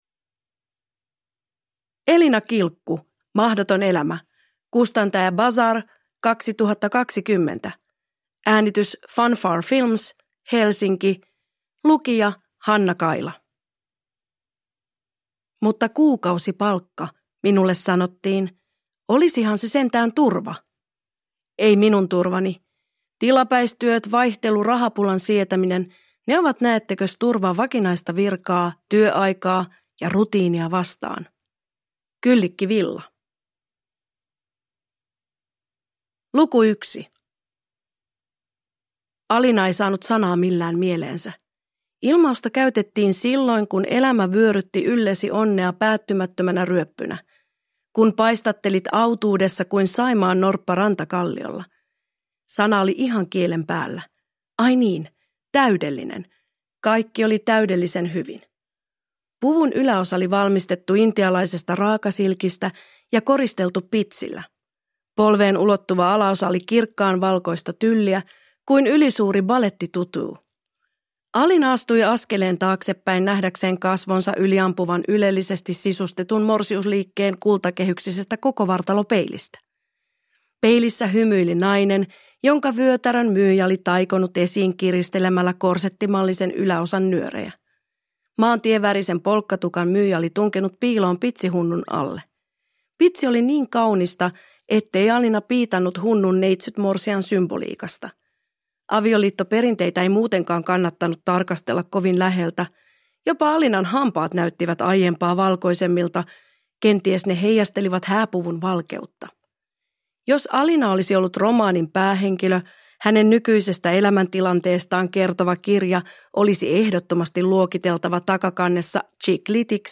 Mahdoton elämä – Ljudbok – Laddas ner